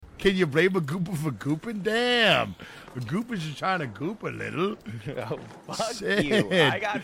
goopa gotta goop Meme Sound Effect